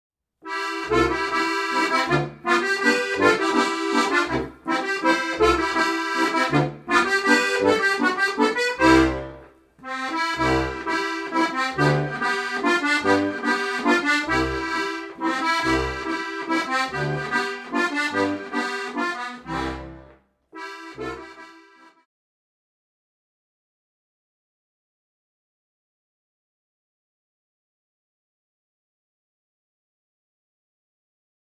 Besetzung: Steirische Harmonika